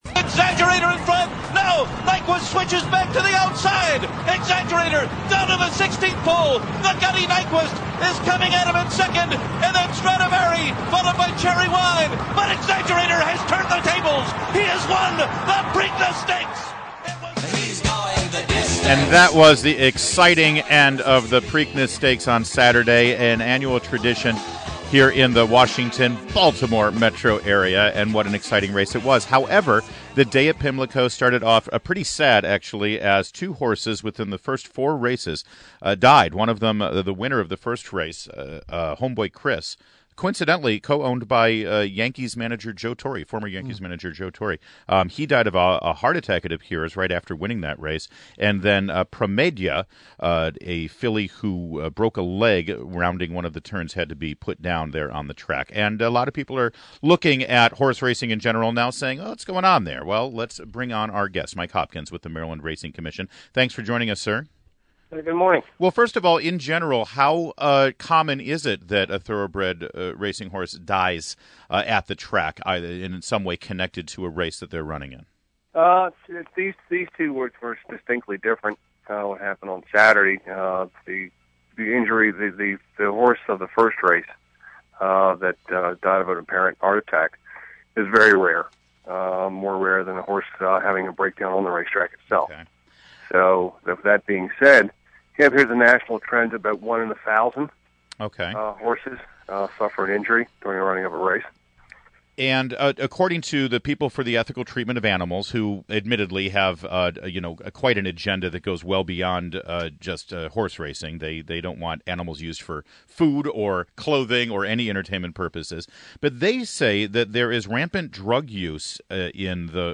INTERVIEW -- MIKE HOPKINS -- Executive Director, MARYLAND RACING COMMISSION